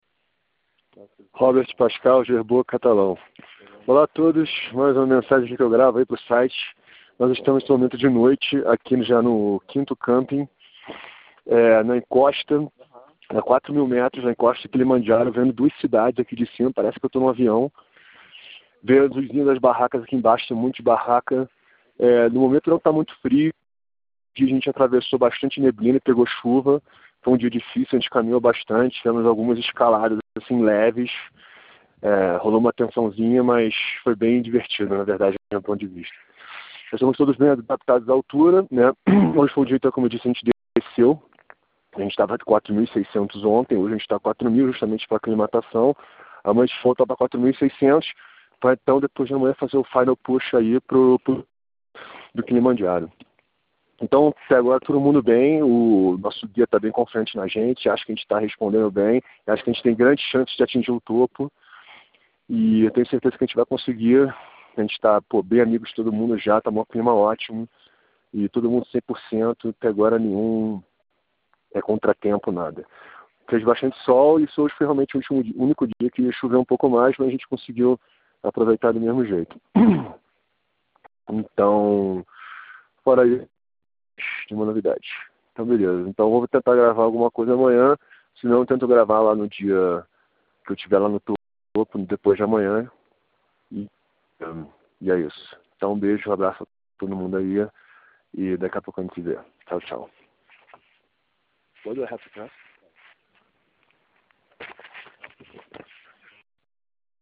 Kilimanjaro Expedition Dispatch